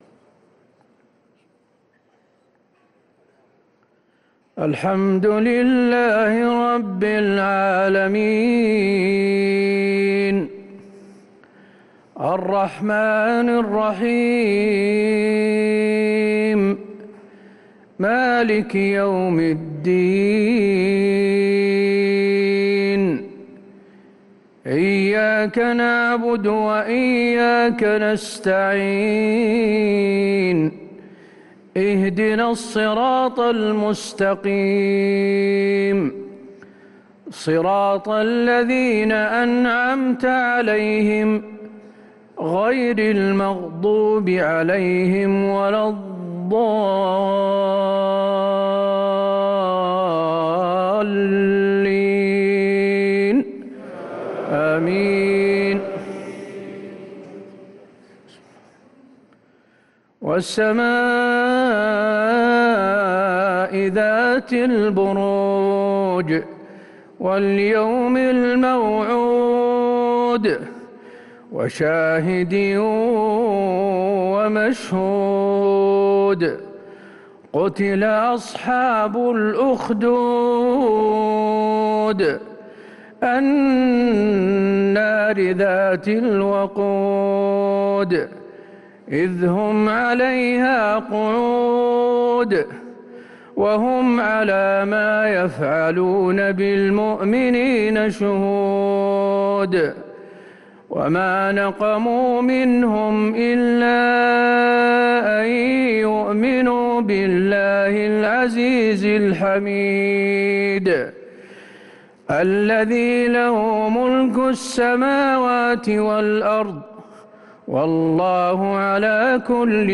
صلاة العشاء